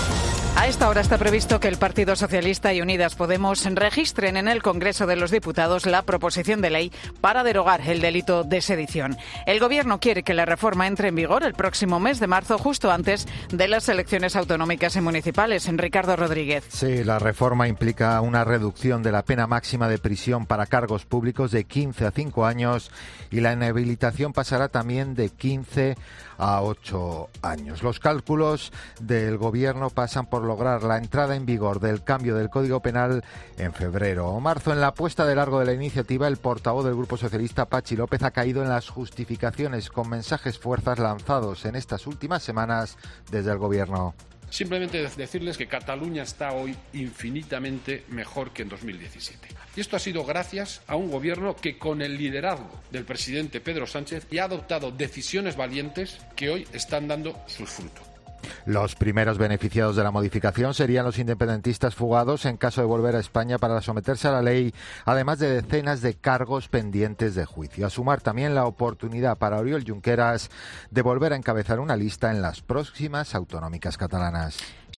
AUDIO: El Gobierno quiere que la reforma del delito de sedición entre en vigor en marzo. Crónica